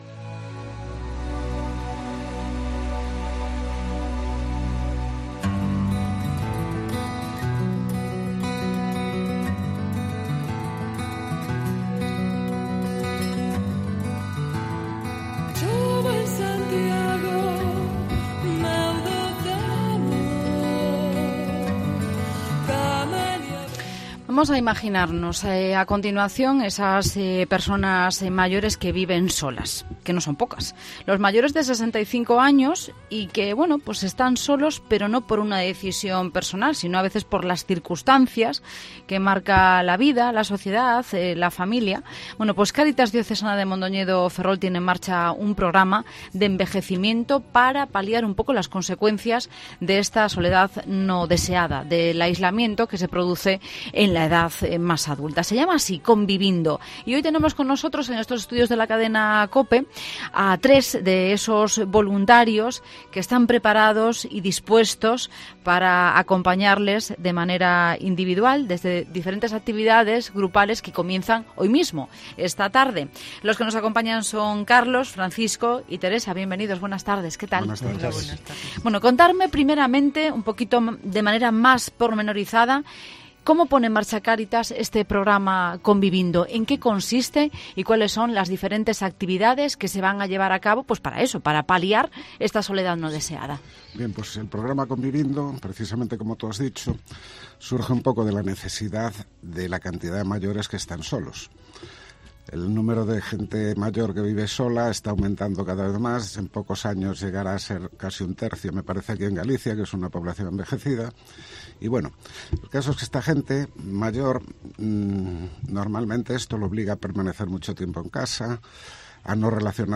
Entrevista a voluntarios del programa "Con-vivindo" de Cáritas